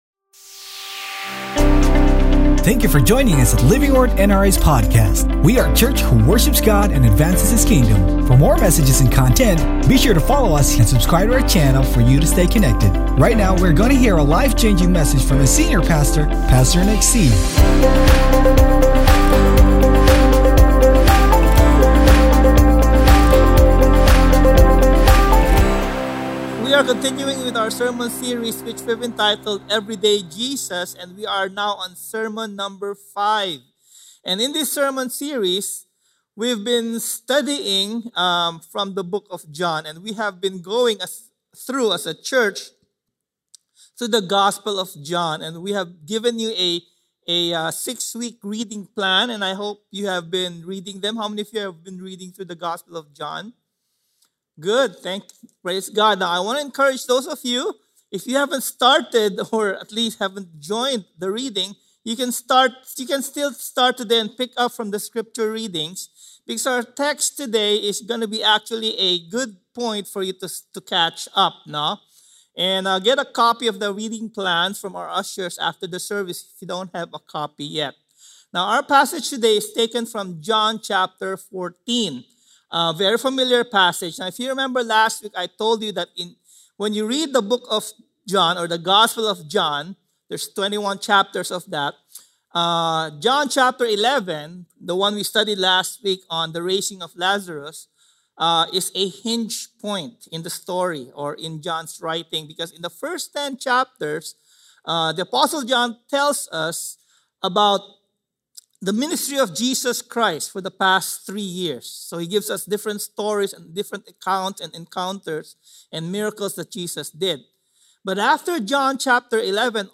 Sermon Title: COPING WITH BAD NEWS Scripture Text: JOHN 14:1-6 Sermon Notes: JOHN 14:1-3 NIV 1 Do not let your hearts be troubled.